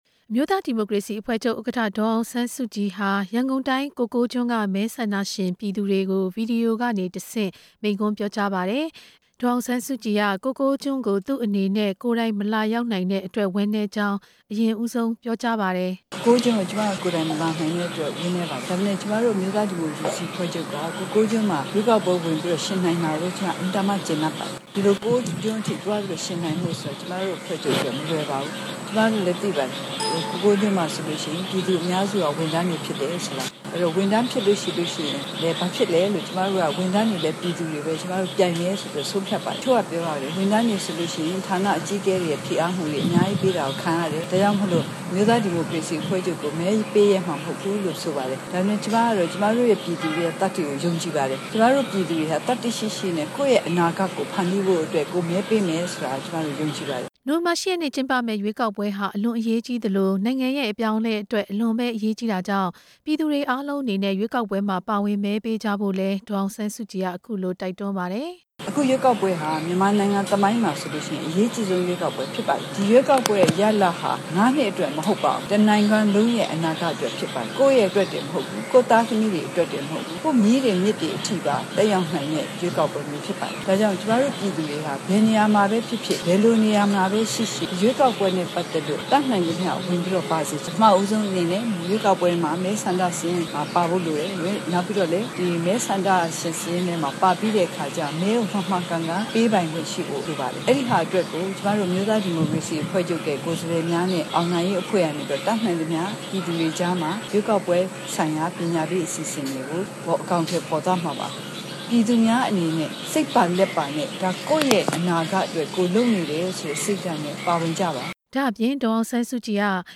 ကိုကိုးကျွန်းမဲဆန္ဒရှင်တွေကို ဗီဒီယိုကနေတဆင့်ပြောတဲ့ ရွေးကောက်ပွဲဆိုင်ရာ မဲဆွယ်ဟောပြောချက်မှာ ထည့်သွင်းပြောဆိုလိုက်တာပါ။